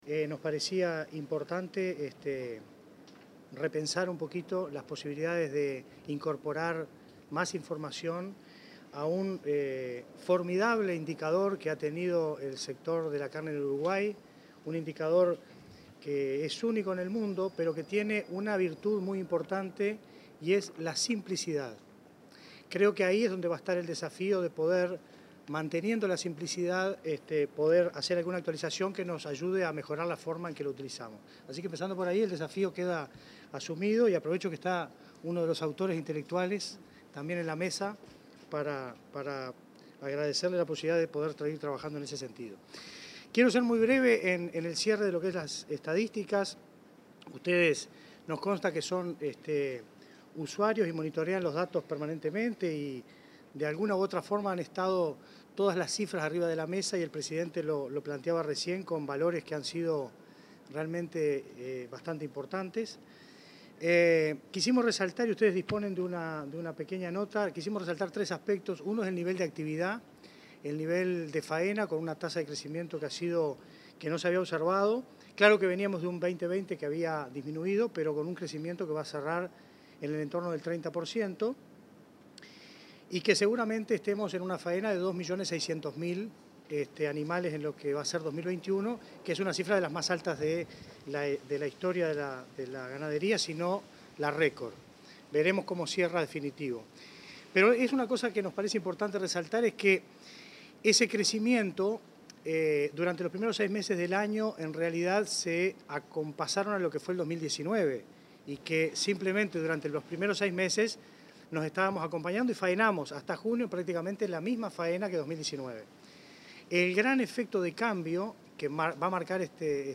Palabras de autoridades del Ministerio de Ganadería e INAC